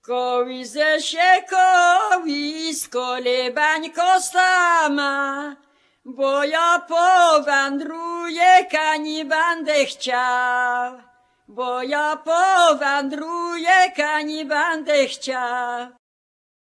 KOŁYSANKI LULLABIES
The lullabies, children's songs, counting-out games and children's play contained on this CD come from the Polish Radio collection.
Half of the recorded tracks are lullabies (tracks 1-30), mostly in recordings from the 1970s, 1980s and 1990s, performed by singers born in the early 20th century.[...]